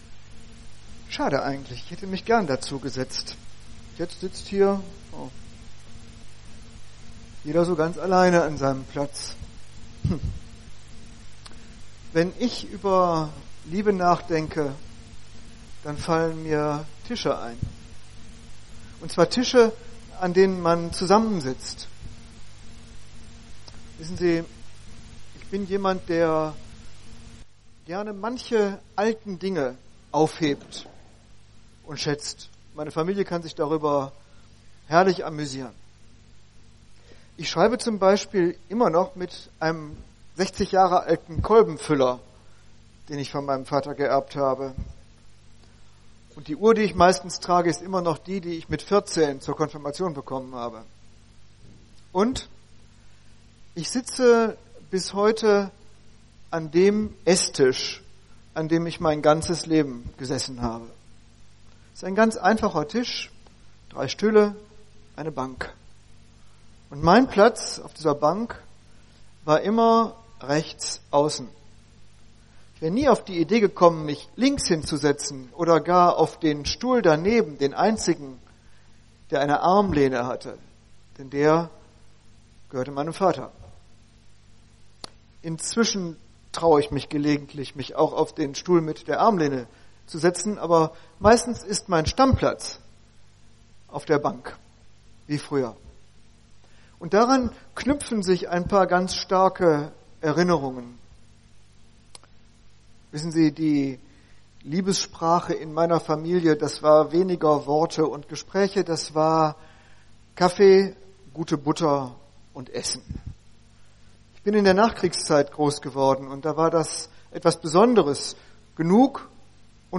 Eine predigt aus der serie "GreifBar Stadthalle."
Veranstaltungen im Rahmen von Greifbar in der Stadthalle